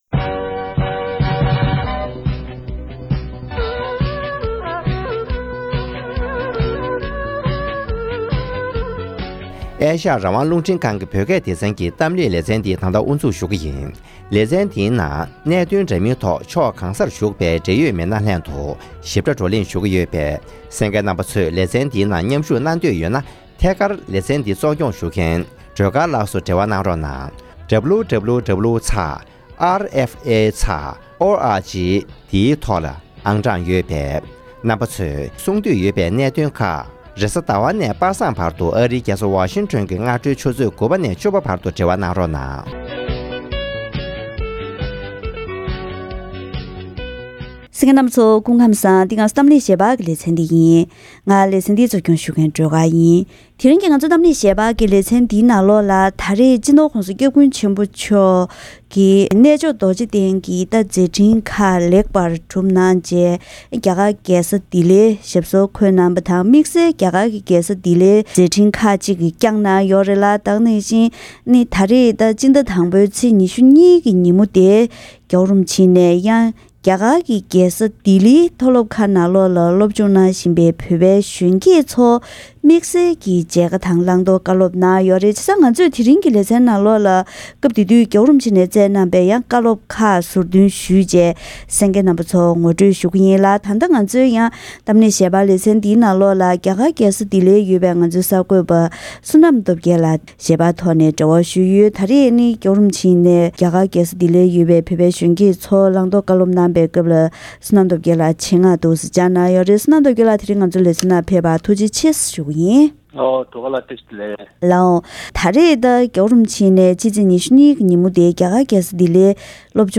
༧གོང་ས་མཆོག་ནས་ལྡི་ལི་ལ་བོད་རིགས་གཞོན་སྐྱེས་ལྔ་བརྒྱ་ལྷག་ཙམ་ལ་ཆོས་དང་སྐད་ཡིག་དེ་བཞིན་དེང་དུས་ཀྱི་ཤེས་ཡོན་སོགས་སློབ་སྦྱོང་བྱ་དགོས་པའི་བཀའ་སློབ།